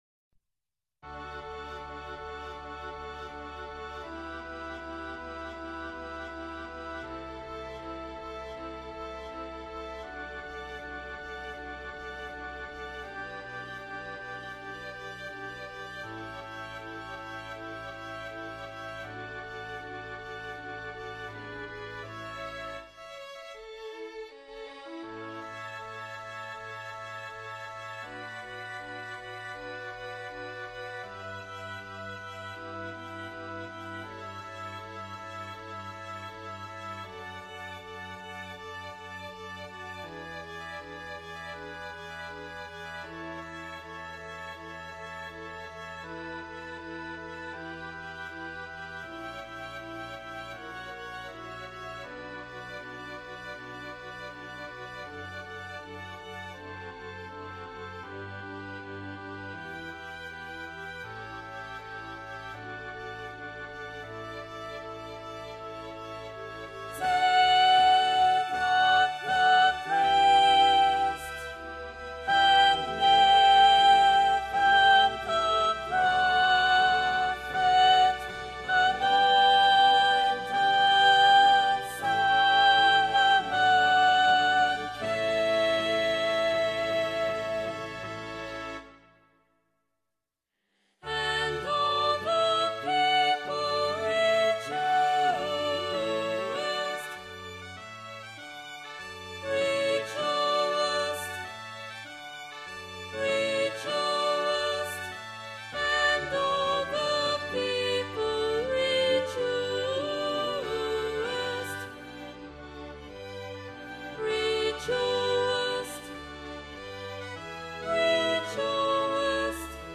Sopranos
Emphasised voice and other voices